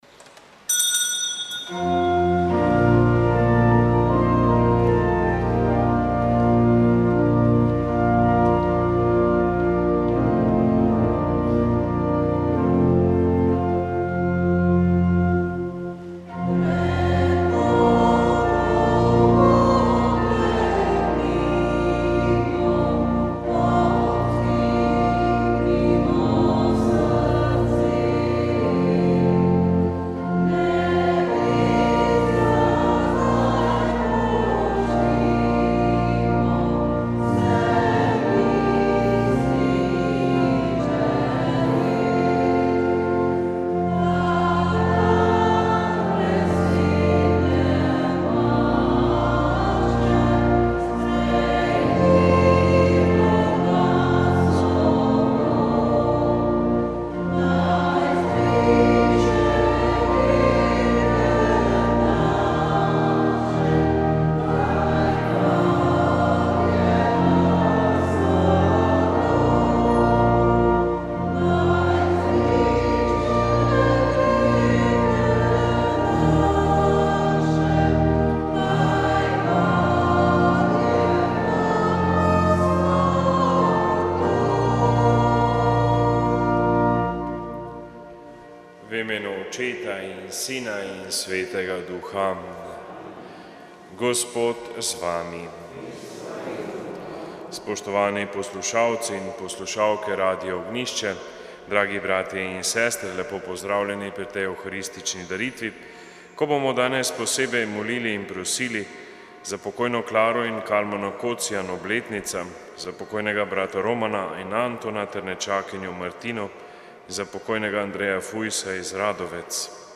Sv. maša iz stolne cerkve sv. Nikolaja v Murski Soboti 18. 7.